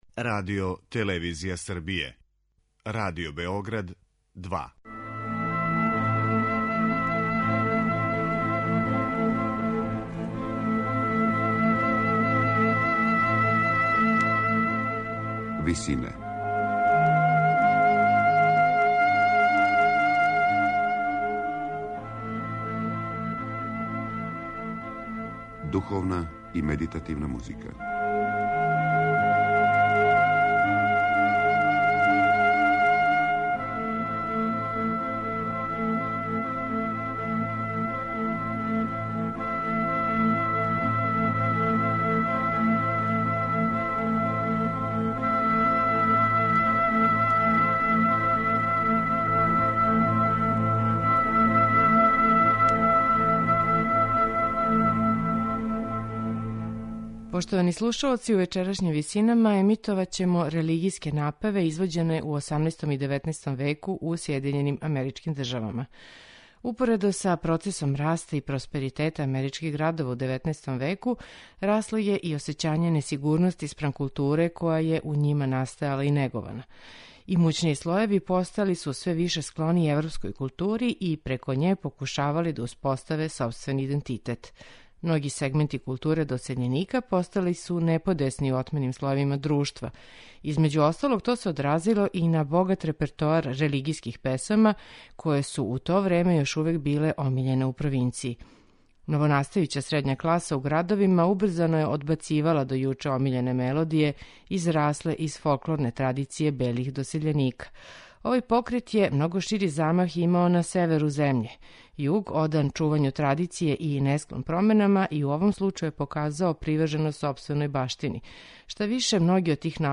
Америчка духовна музика 18. и 19. века
Духовни напеви који су током 18. и 19. века извођени у црквама у Сједињеним Aмеричким Државама, имали су снажно упориште у старим енглеским и шкотским народним мелодијама.
У вечерашњим Висинама емитоваћемо осамнаест напева америчке духовне музике 18. и 19. века у извођењу чланова ансамбла The Boston Camerata, под управом Џоела Коена.